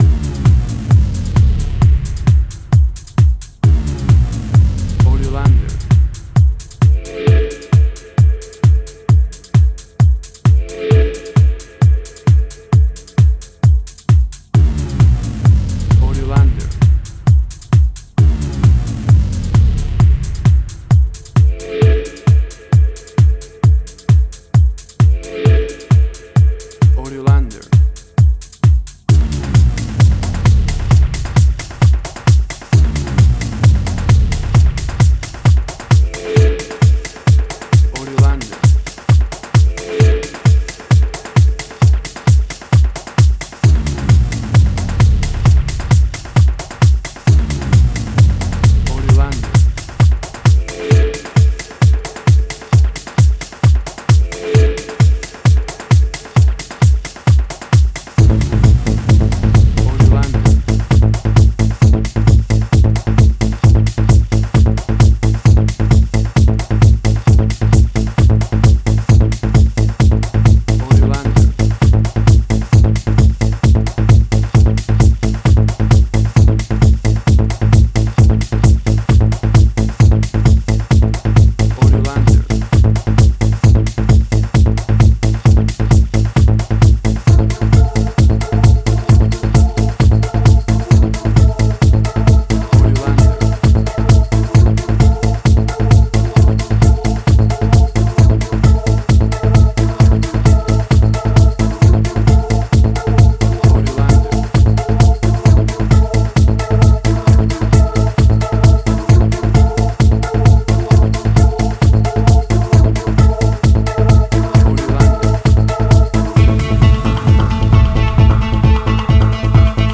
House.
WAV Sample Rate: 16-Bit stereo, 44.1 kHz
Tempo (BPM): 132